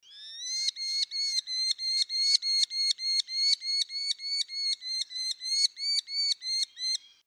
No. 364: Le cri du Faucon émerillon
Le faucon émerillon à un cri très distinctif facile à reconnaître.